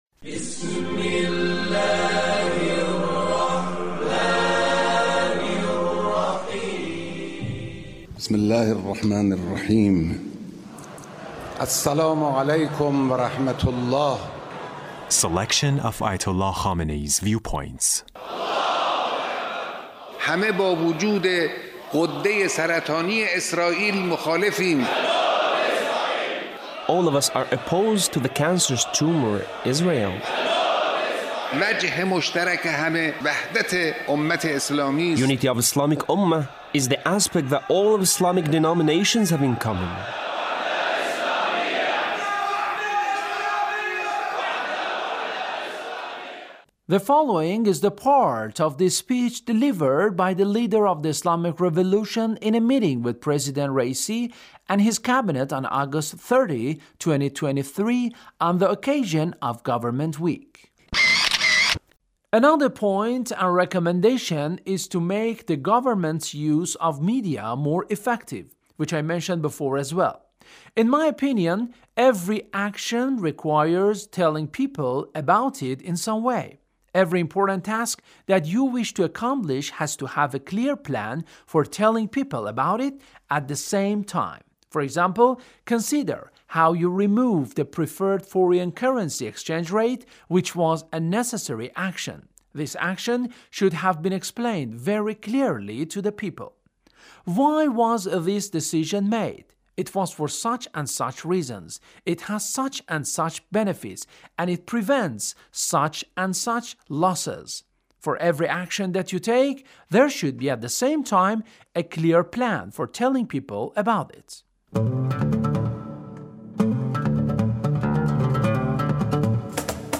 Leader's Speech with Government Officials